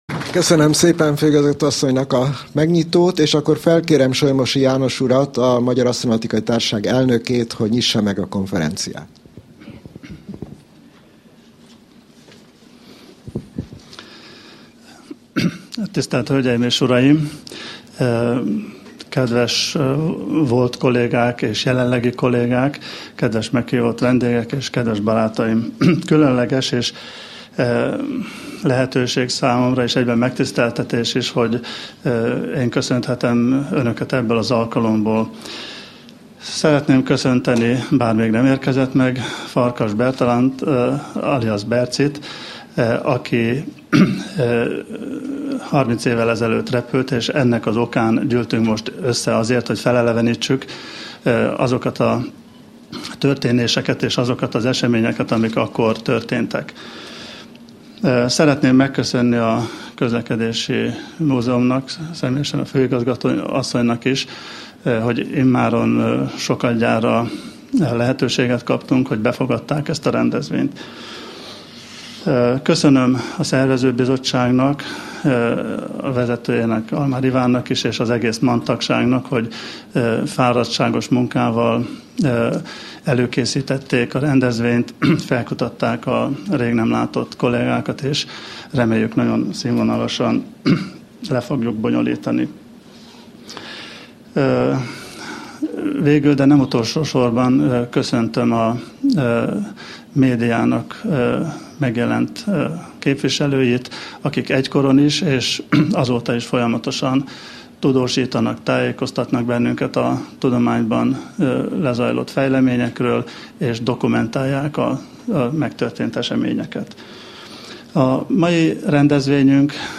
Magyar Asztronautikai Társaság - 2010. május 13. (Közlekedési Múzeum)
A Magyar Asztronautikai Társaság ezért egy nyilvános baráti beszélgetésre hívta össze e nagy esemény még elérhető, élő tanúit.